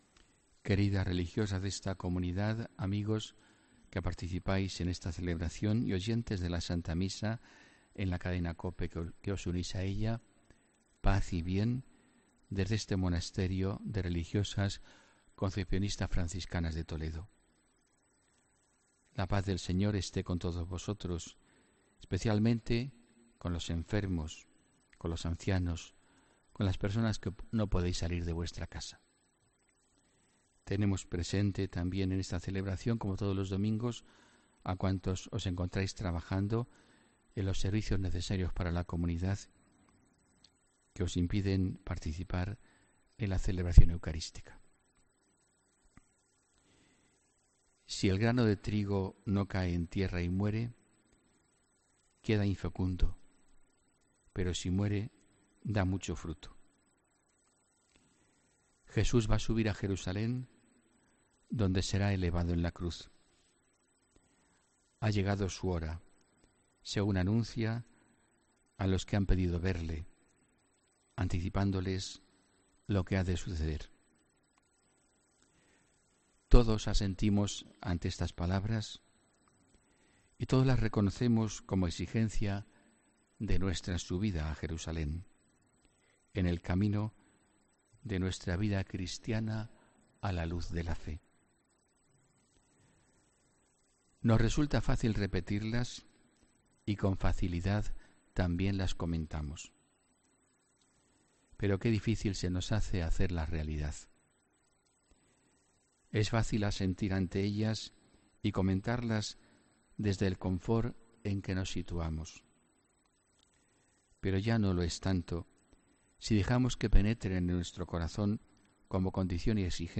HOMILÍA 18 MARZO 2018